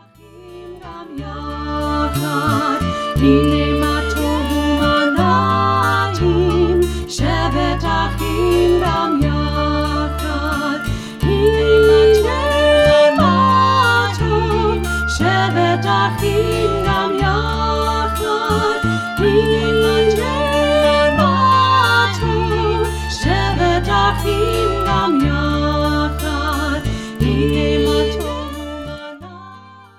Traditional songs in Hebrew & English.
(Folk)